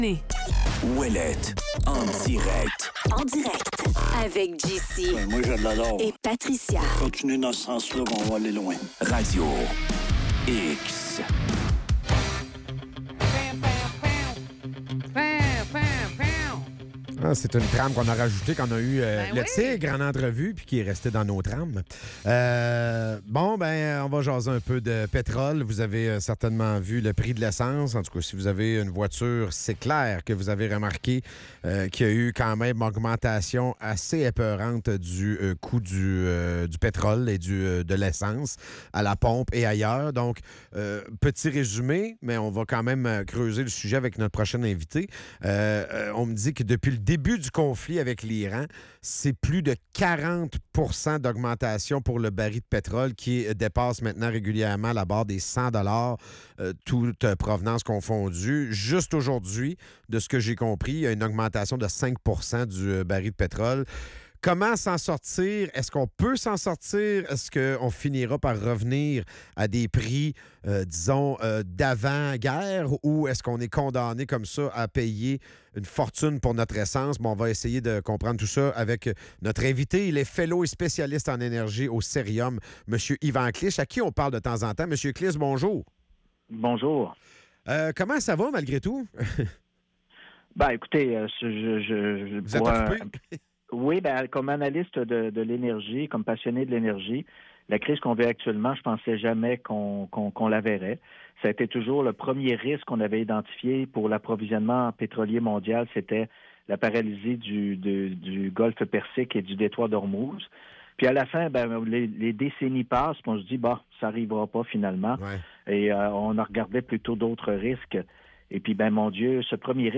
En chronique